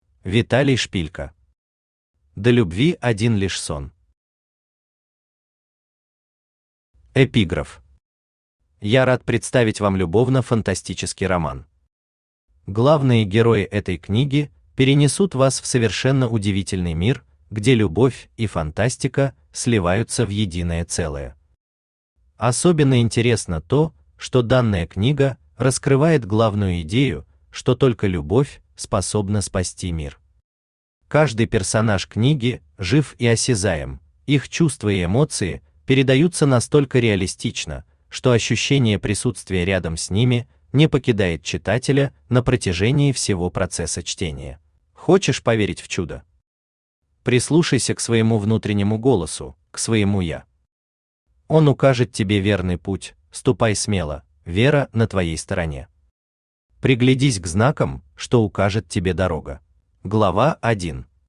Аудиокнига До любви один лишь сон…
Автор Виталий В. Шпилько Читает аудиокнигу Авточтец ЛитРес.